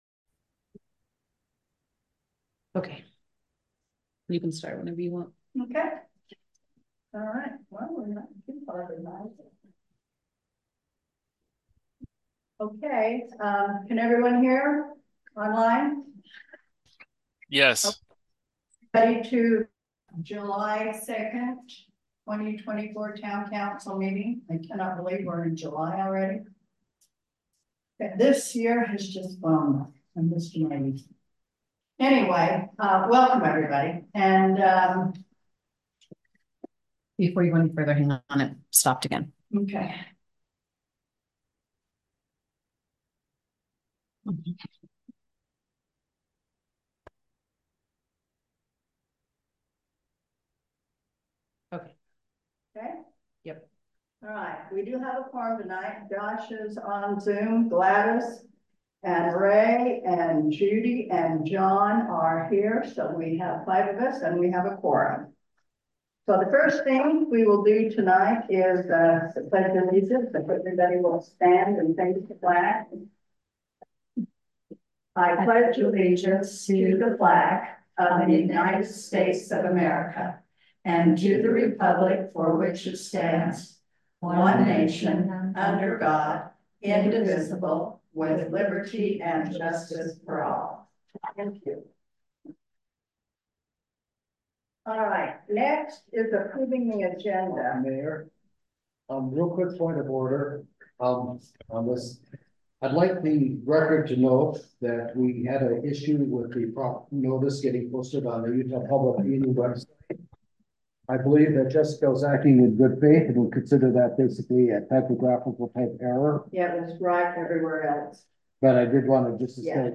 The Boulder Town Council will hold its regular meeting on Tuesday July 2, 2024, starting at 7:00 pm at the Boulder Community Center Meeting Room, 351 No 100 East, Boulder, UT.
The Audio Recording stopped recording after the council went into executive session. There is no audio recording for the rest of the meeting.